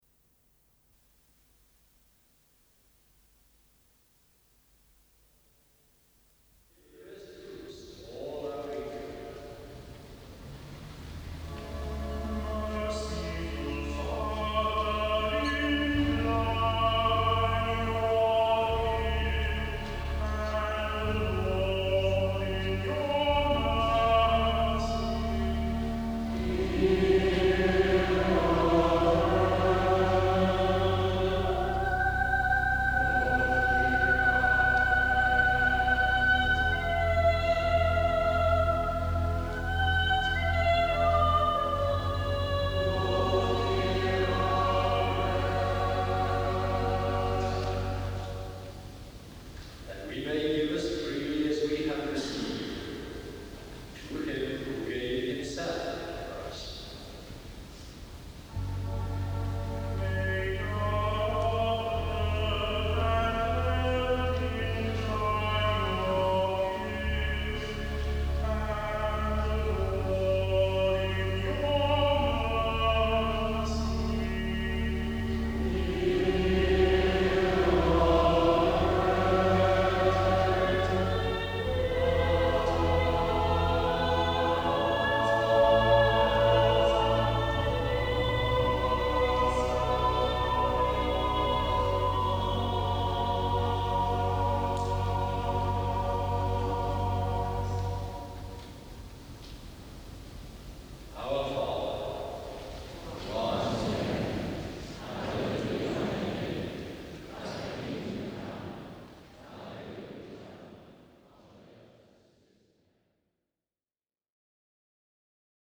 Choral Responses: Praise and Preach – Swansea, 6/87